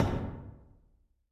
tbd-station-14/Resources/Audio/Effects/Footsteps/hull1.ogg at d1661c1bf7f75c2a0759c08ed6b901b7b6f3388c